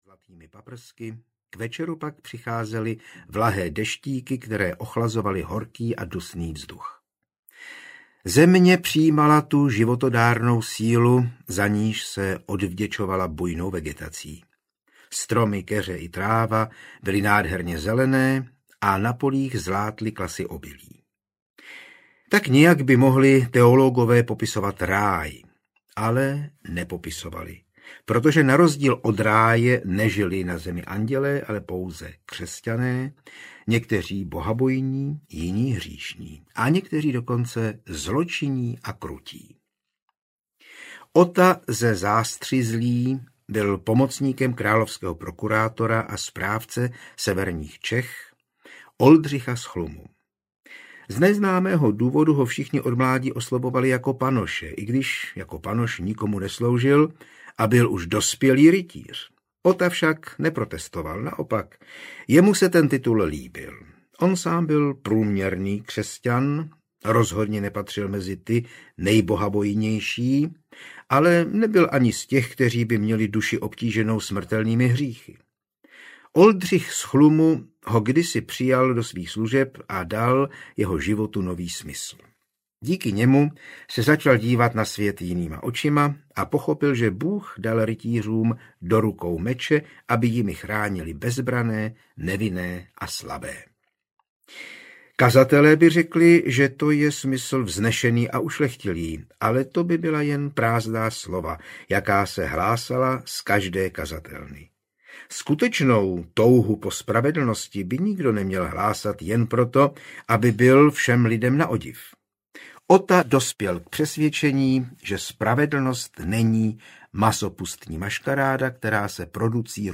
Msta písecké panny audiokniha
Ukázka z knihy
• InterpretJan Hyhlík